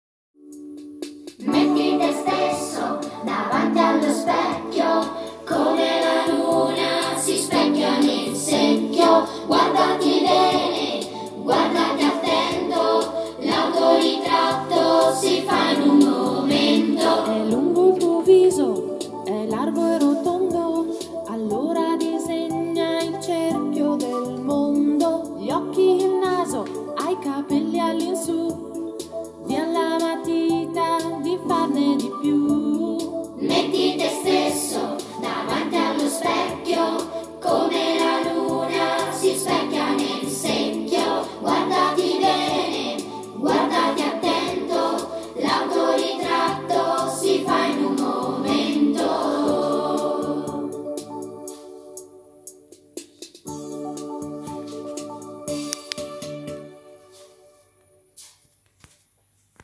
BATTI LE MANI A TEMPO DI MUSICA (scherzavo... ma se lo hai fatto  stai  andando  alla grande!)